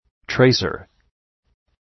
Προφορά
{‘treısər}